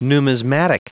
Prononciation du mot numismatic en anglais (fichier audio)